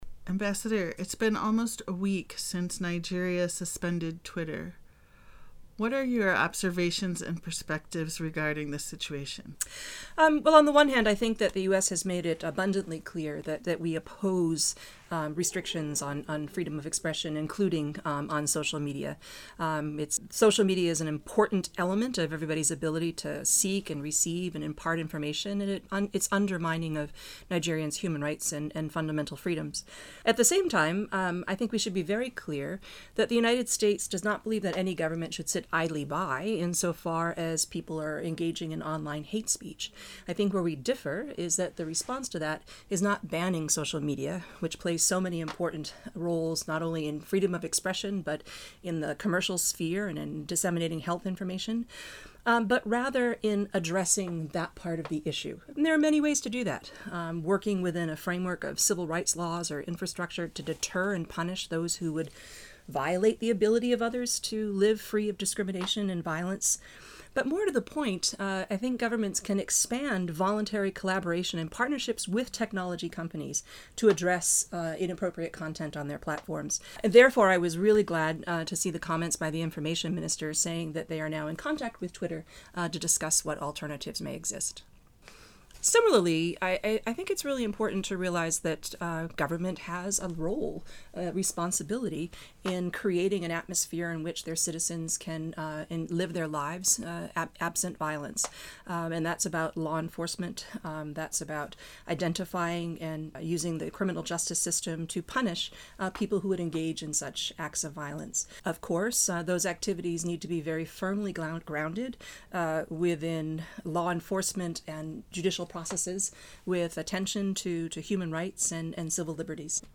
Listen to Ambassador Leonard speak below: